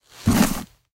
mask_clean.ogg